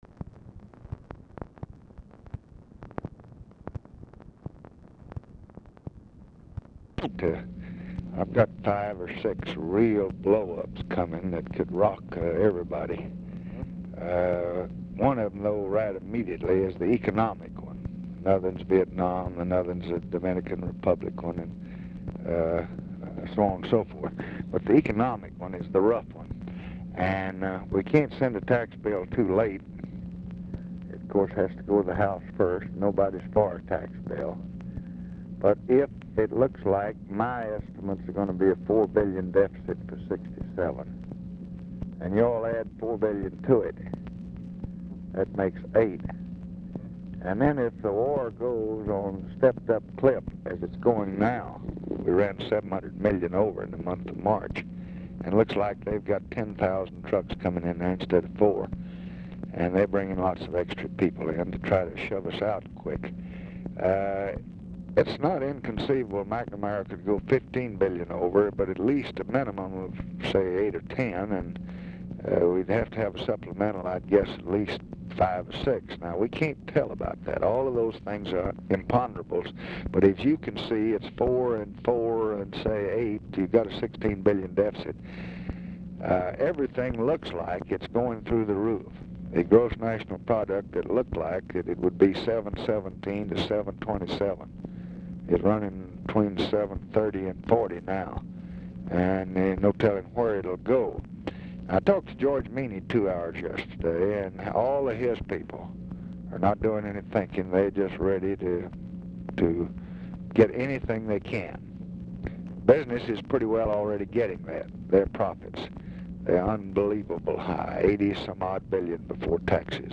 Telephone conversation # 10115, sound recording, LBJ and MIKE MANSFIELD, 5/12/1966, 11:00AM | Discover LBJ
RECORDING STARTS AFTER CONVERSATION HAS BEGUN
Format Dictation belt
Location Of Speaker 1 Oval Office or unknown location